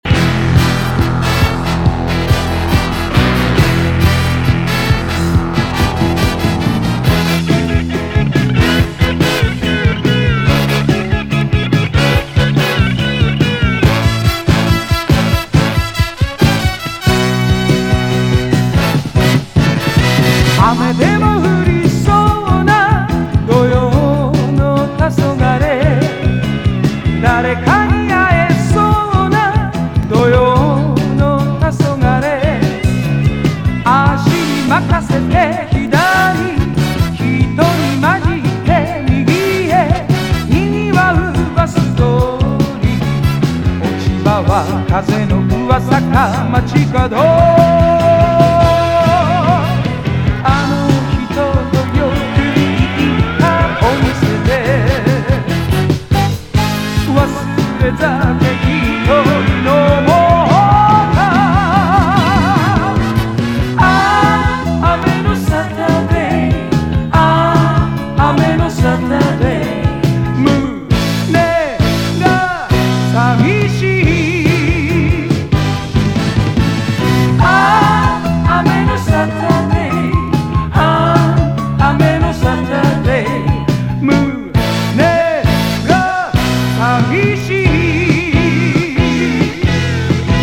ELECTRONICA / 現代音楽 / ELECTRONICS / JAPANESE / SPACE / COSMIC